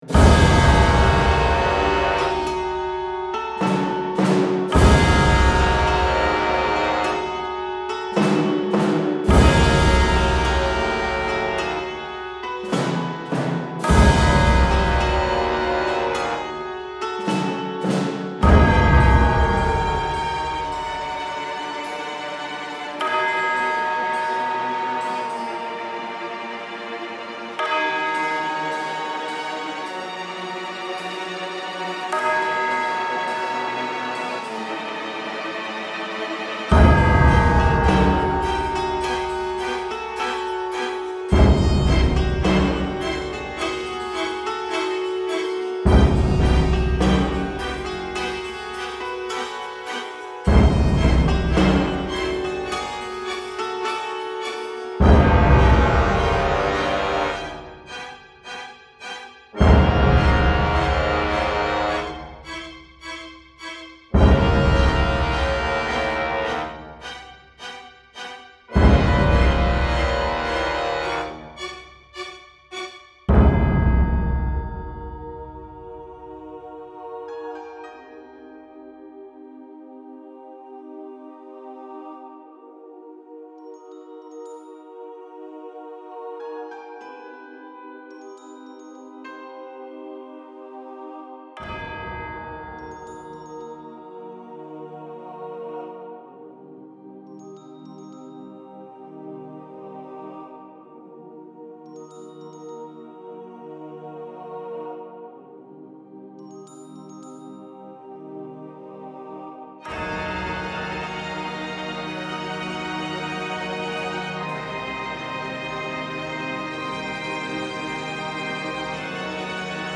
Style Style Orchestral, Soundtrack
Mood Mood Dark, Intense, Mysterious +2 more
Featured Featured Choir, Drums, Organ +2 more
BPM BPM 105